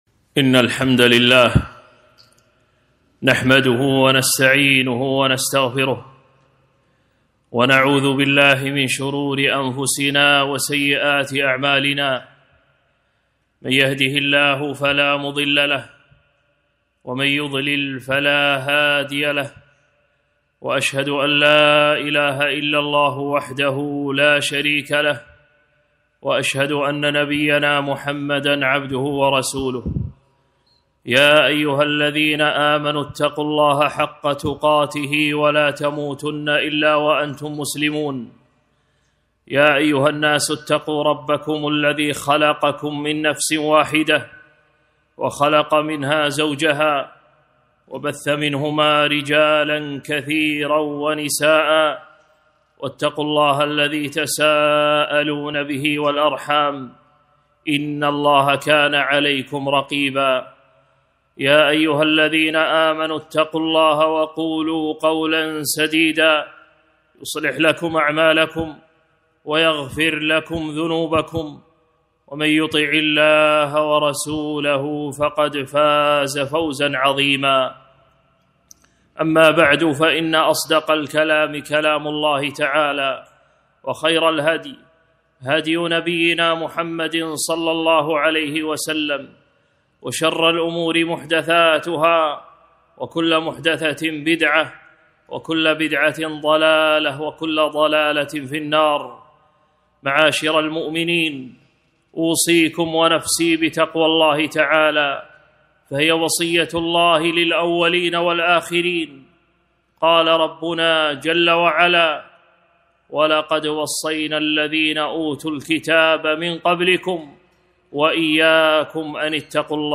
خطبة - وصايا في الشتاء